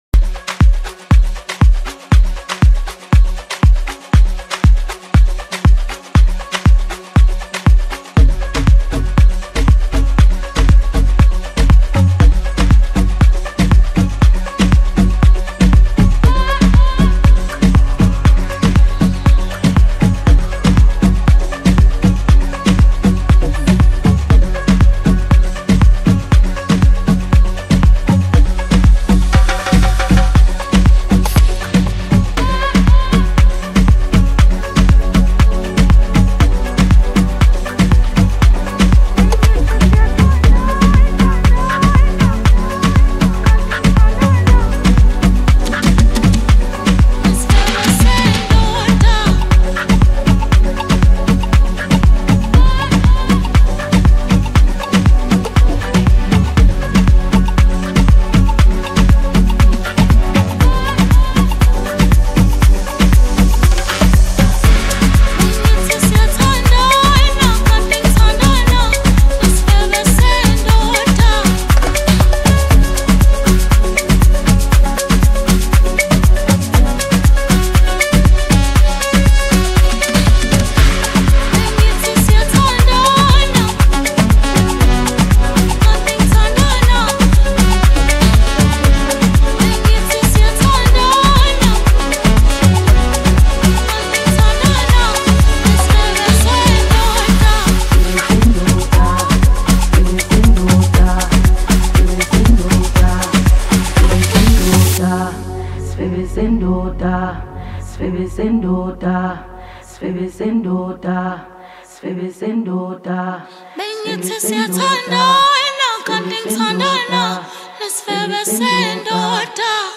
Home » Amapiano » DJ Mix » Hip Hop
captivating and harmonious tune